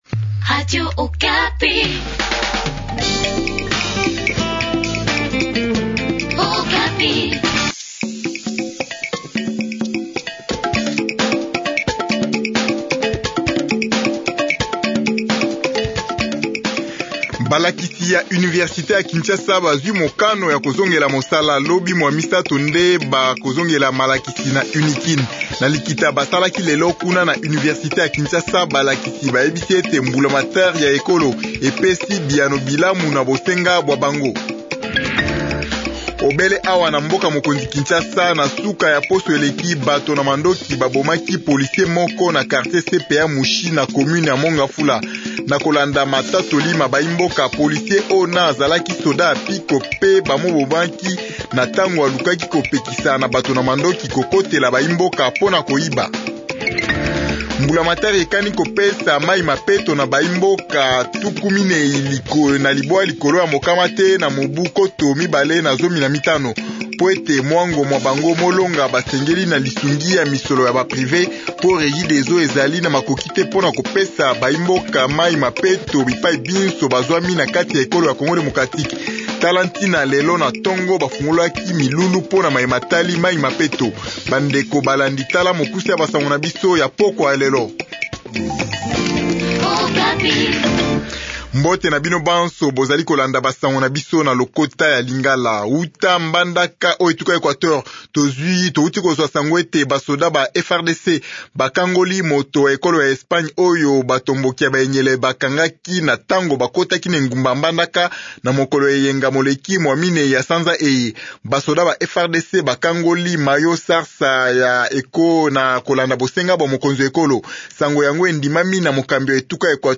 Journal Lingala du soir